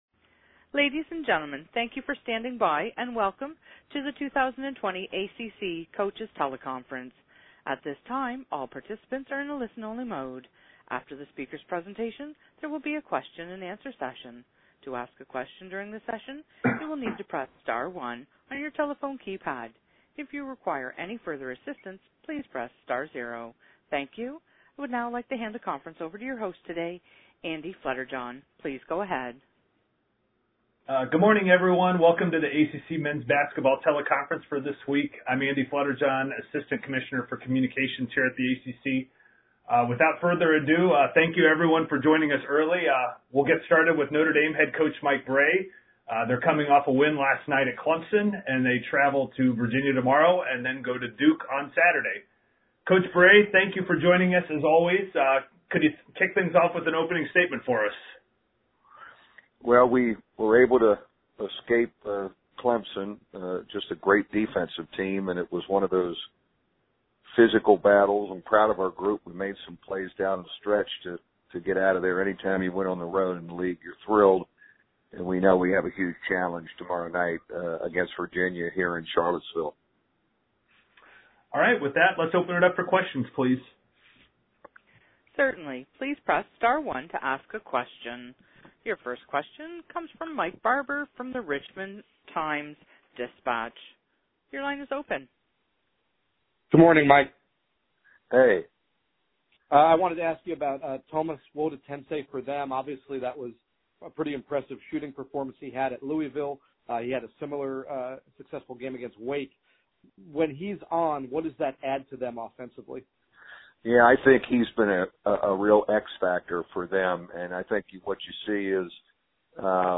ACC Men's Basketball Teleconference - Feb. 10